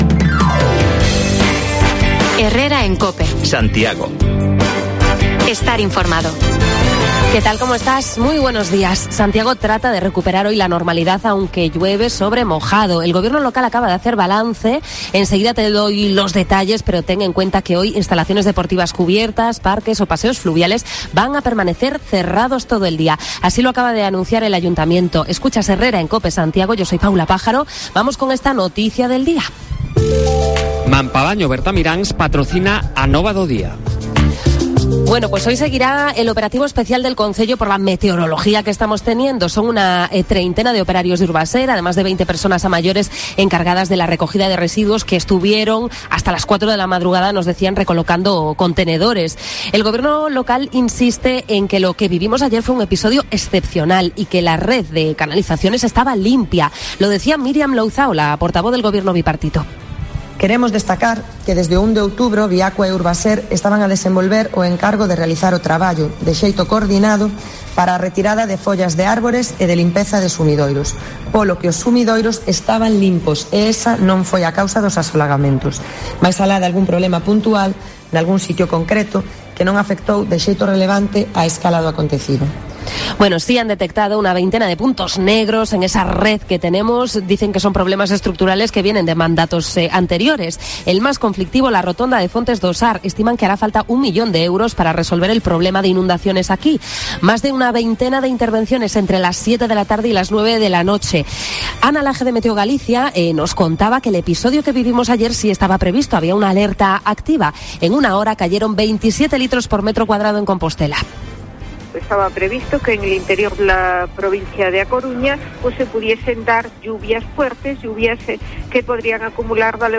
La lluvia, protagonista en Santiago por las incidencias que provoca: analizamos desde la calle la situación de las últimas horas y asistimos a una nueva inundación en la rotonda de Fontes do Sar, un punto negro de inundaciones